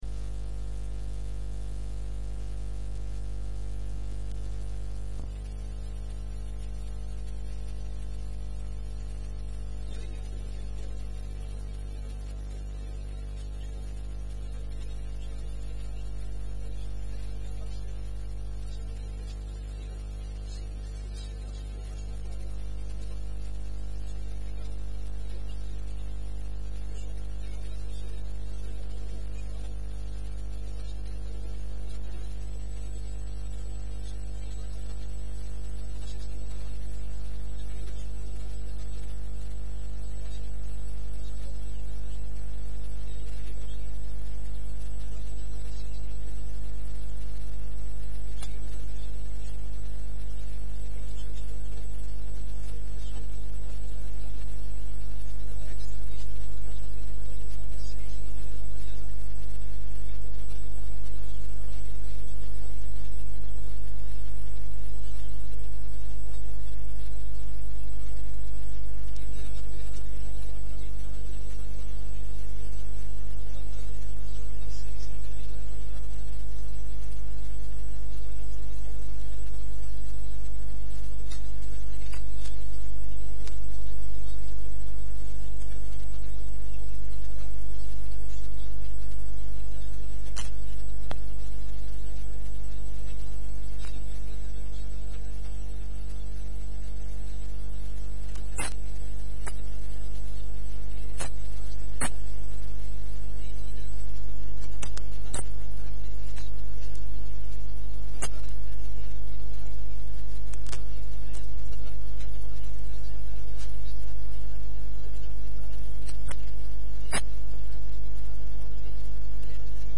Predicaciones bíblicas
17.-Entrevista-consideraciones-sobre-noviazgo-y-matrimonio.mp3